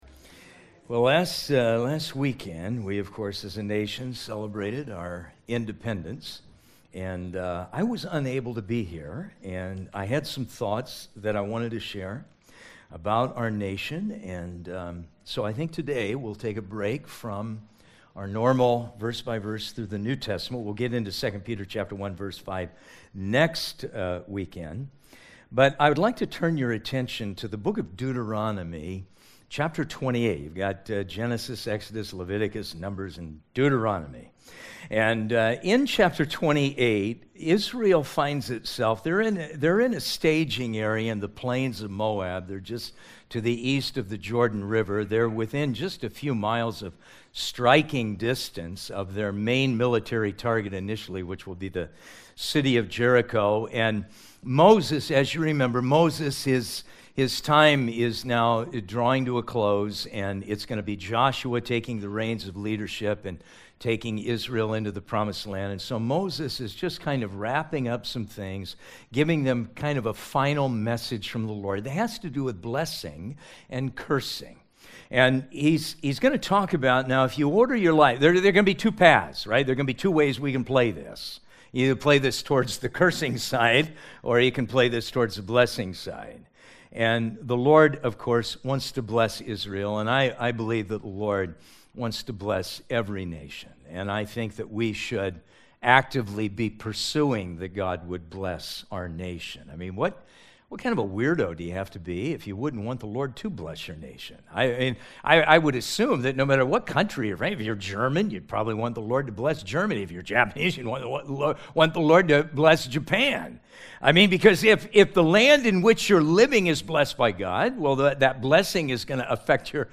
We feature verse by verse teachings through the Bible, topical messages, and updates from the staff and lead team.
July12th1stservice.mp3